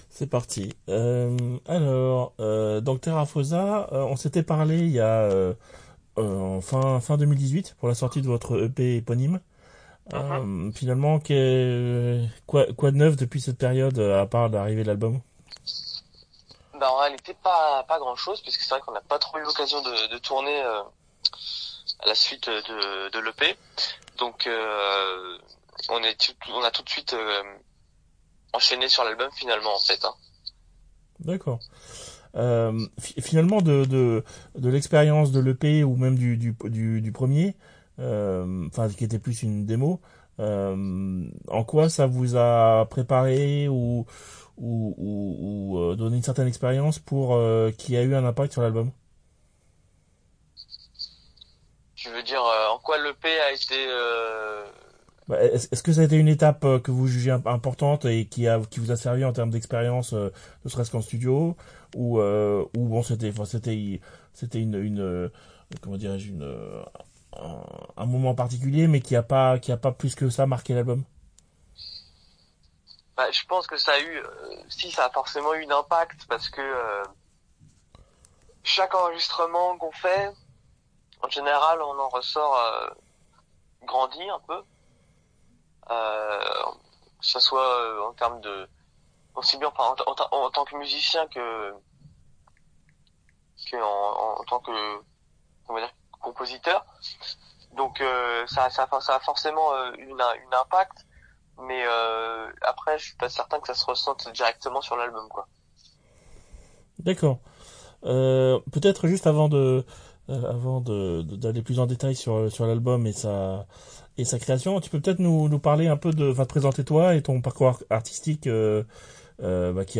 THERAPHOSA (interview